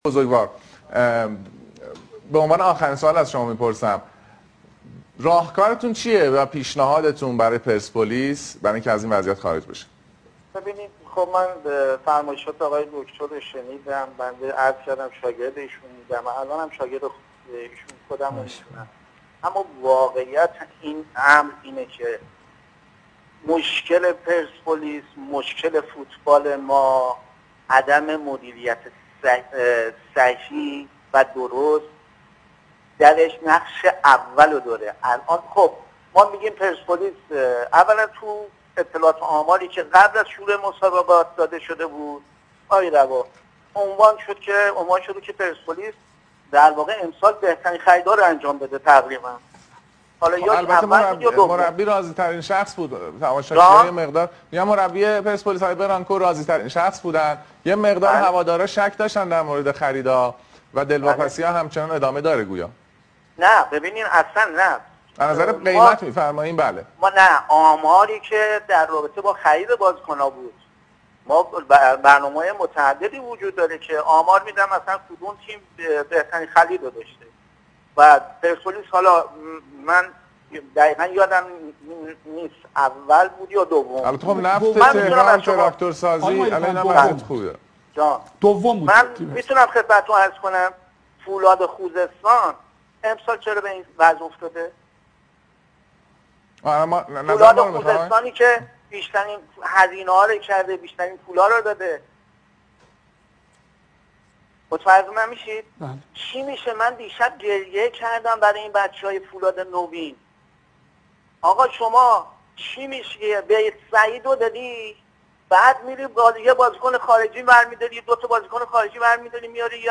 مایلی کهن پیشکسوت تیم پرسپولیس در برنامه تلویزیونی شبکه ورزش، راهکارش برای حل مشکلات این تیم را عنوان کرد.